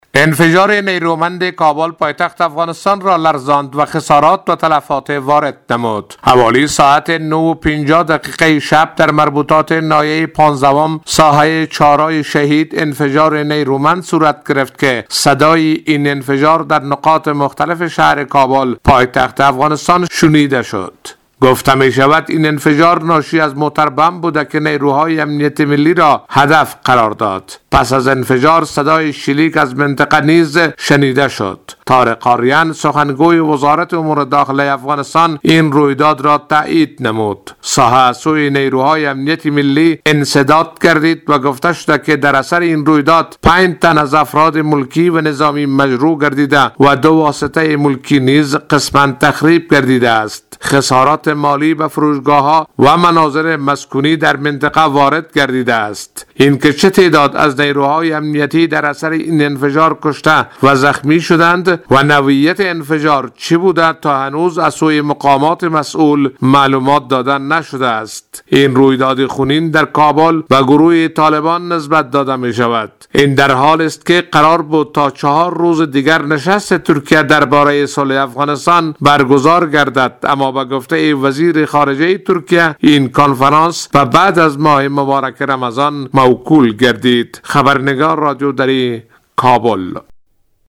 خبرنگار رادیودری از کابل: